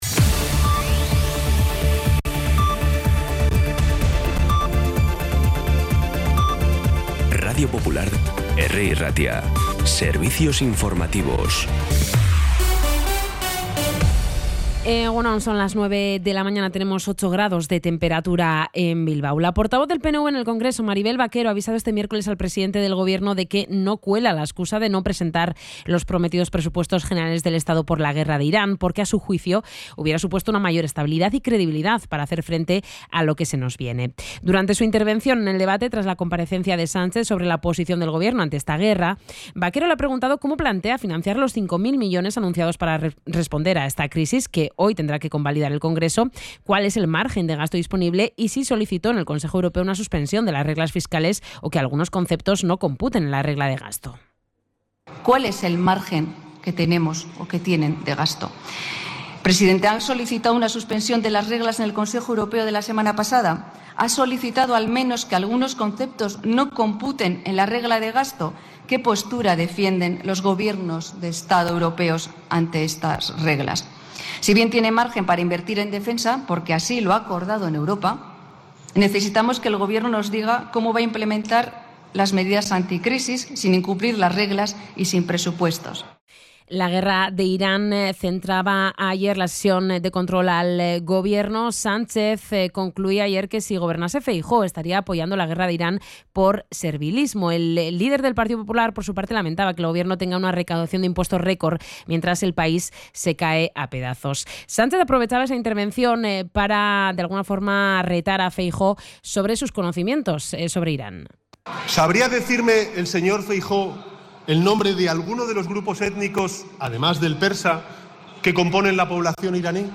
Las noticias de Bilbao y Bizkaia de las 9 , hoy 26 de marzo
Los titulares actualizados con las voces del día.